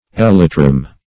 Elytrum - definition of Elytrum - synonyms, pronunciation, spelling from Free Dictionary
Elytron \El"y*tron\ (?; 277), Elytrum \El"y*trum\ (-tr?m)n.; pl.